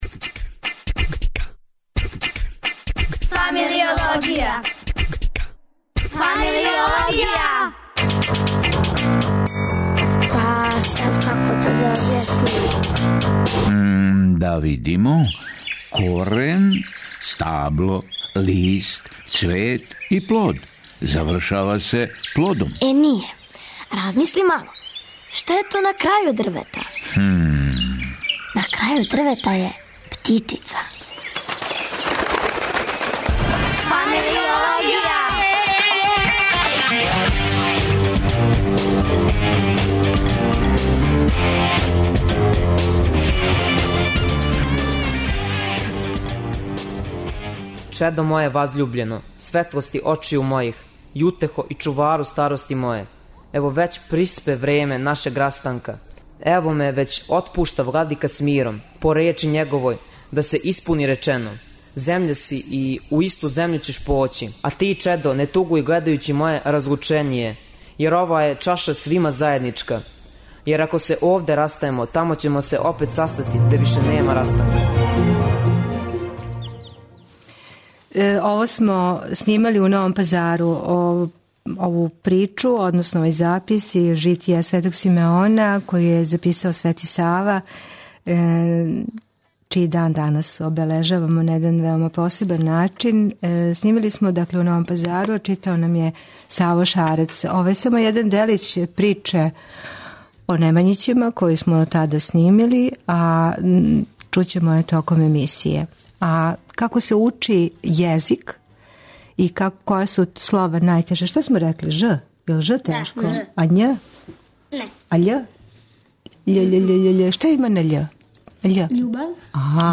Српски језик се учи на разне начине: у кући, у школи, на курсевима... У данашњој емисији која се емитује из Аустријског радија, гости,наши људи и деца која живе, расту и раде у Бечу, говоре о породичном животу, о учењу и чувању српског језика, о малом хору, окупљањима.